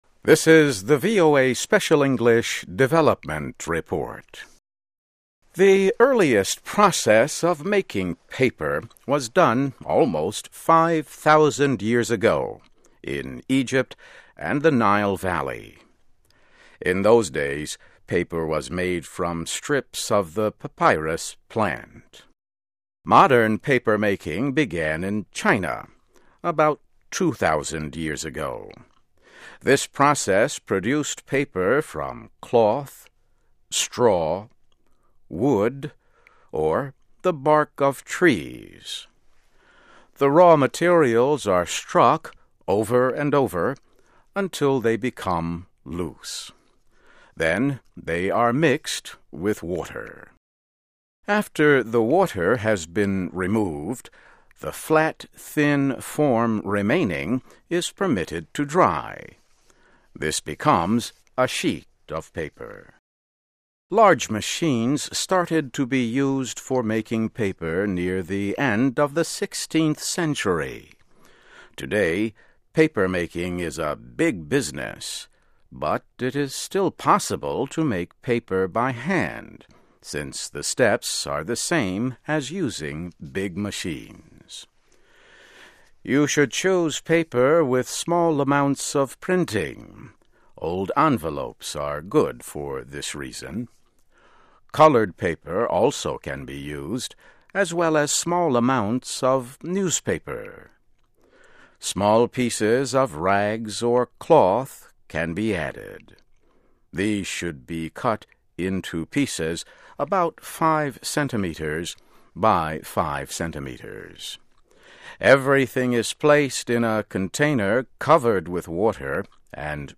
Topic: Modern paper-making began in China about 2,000 years ago. Transcript of radio broadcast.